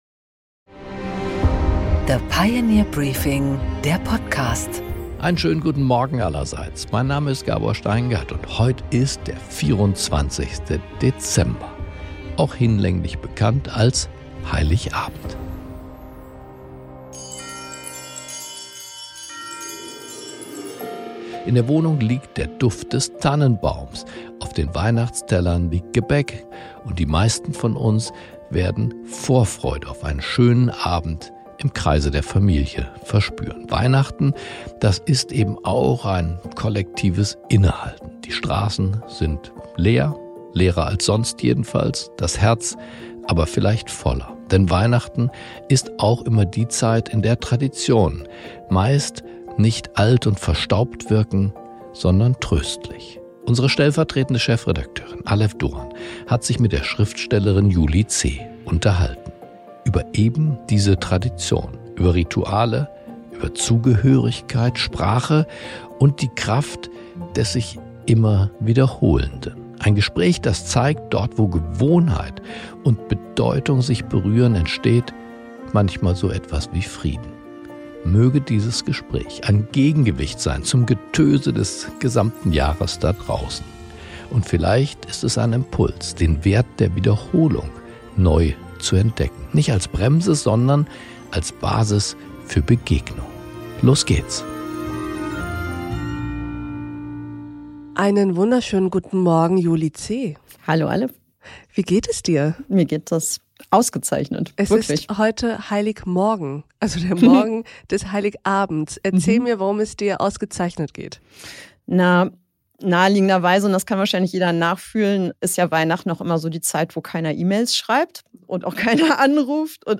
Ein Gespräch über familiäre Brüche, stille Feiertage und die Sehnsucht nach Verlässlichkeit.